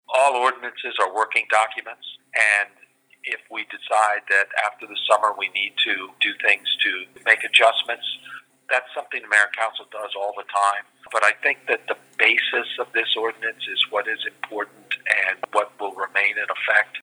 He tells the Talk of Delmarva if there are any plans to revisit or adjust the policy based on how things go this summer…